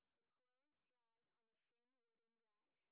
sp15_street_snr20.wav